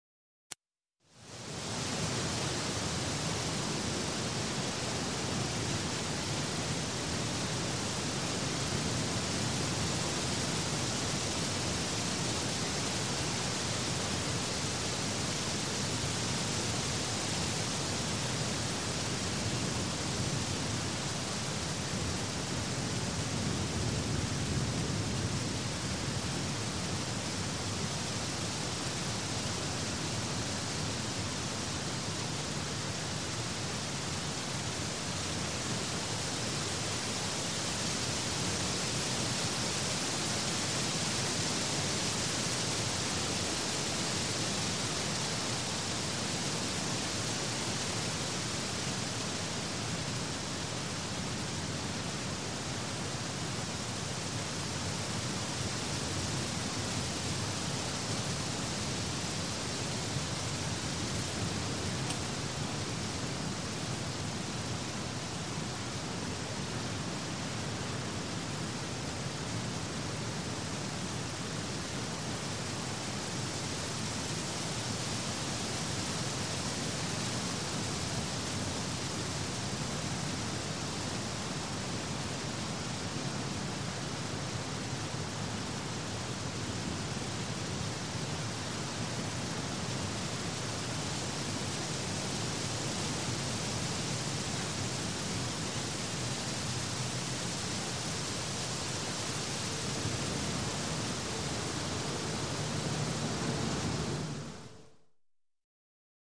Ветер качает деревья, на побережье
Тут вы можете прослушать онлайн и скачать бесплатно аудио запись из категории «Погода».